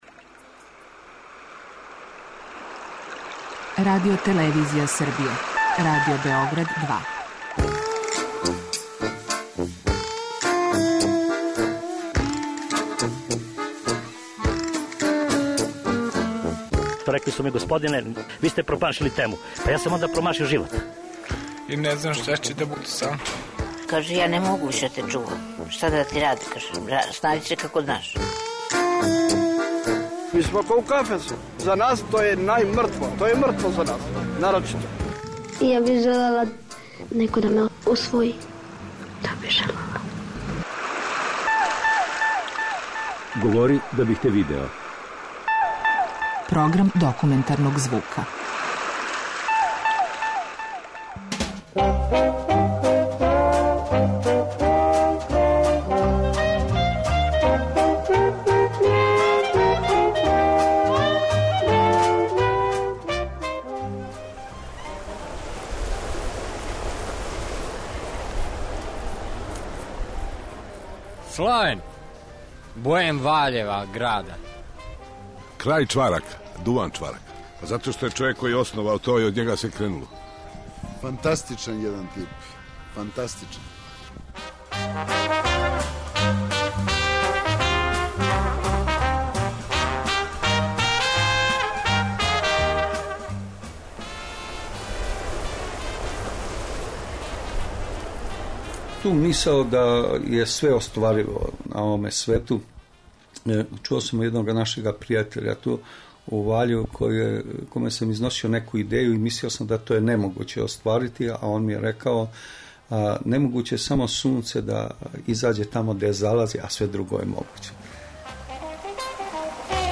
Документарни програм: Краљ чварака